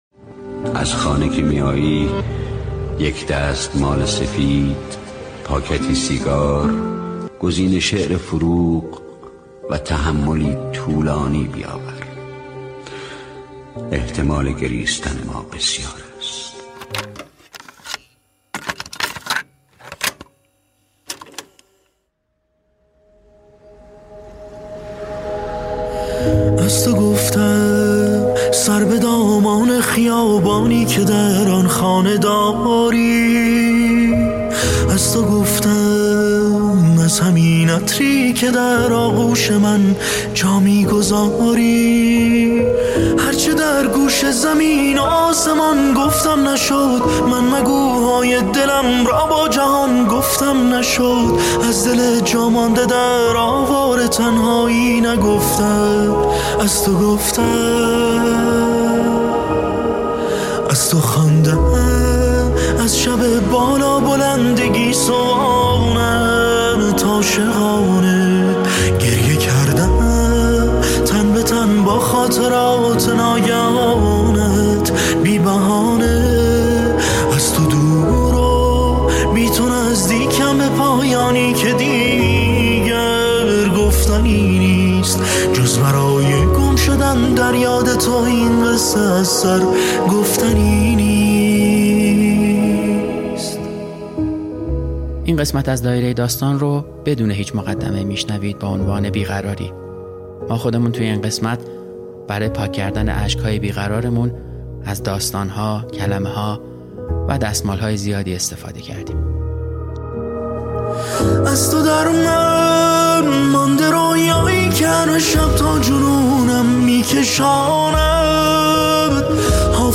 روی قطعه پیانویی